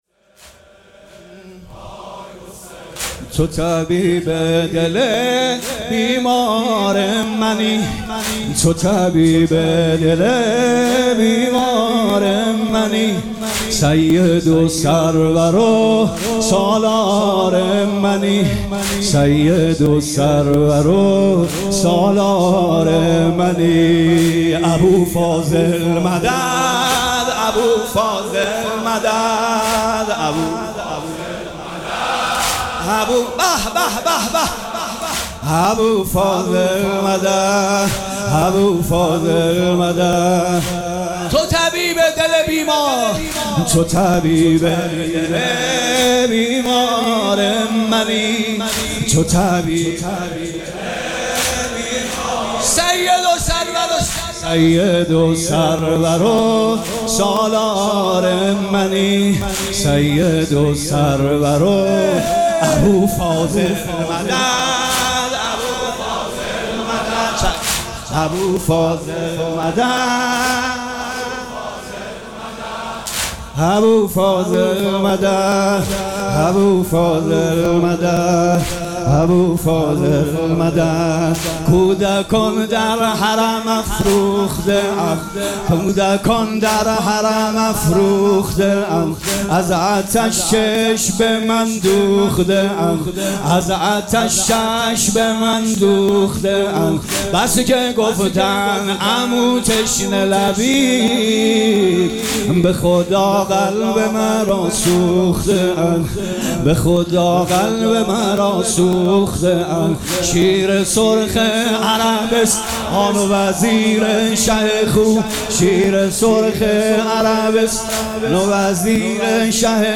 مراسم عزاداری شب نهم محرم الحرام ۱۴۴۷
هیئت ریحانه الحسین سلام الله علیها